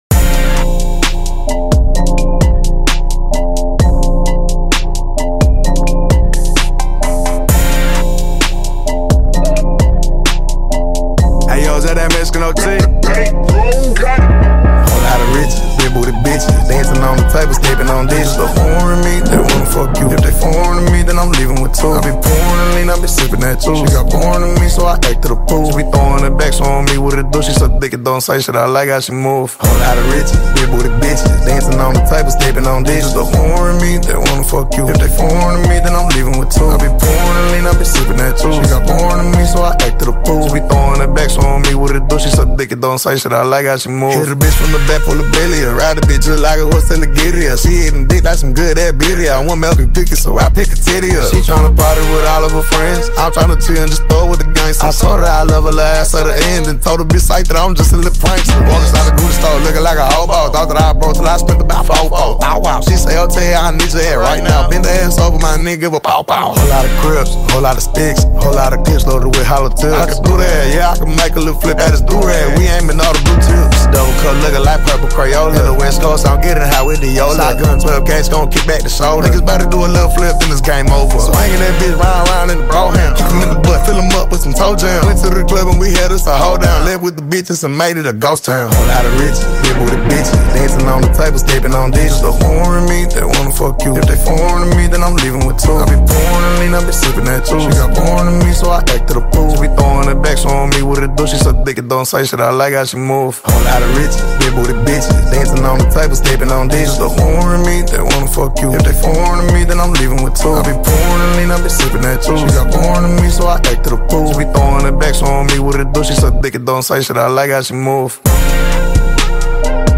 blending rhythm, soul, storytelling, and modern sound